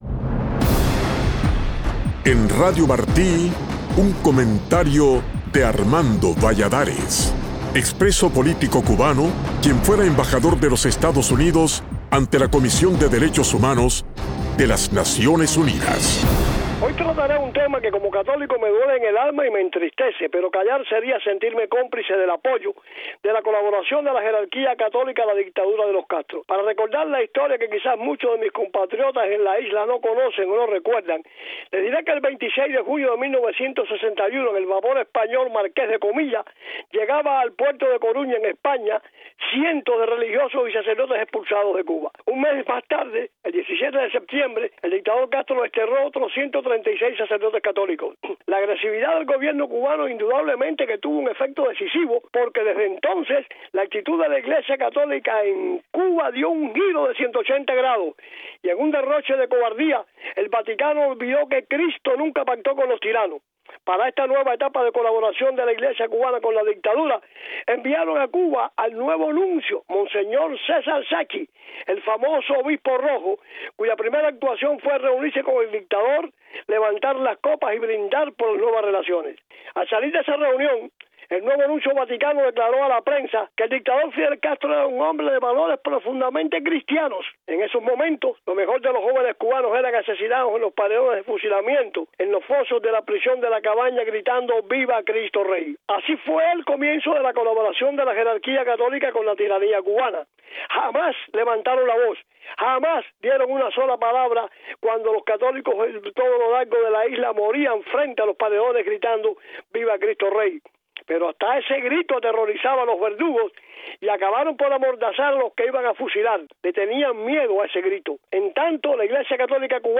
En su comentario de hoy, el embajador Valladares toca el tema de la colaboración de la jerarquía católica en Cuba con la dictadura de los Castro, y recuerda una historia que quizás muchos cubanos no conocen.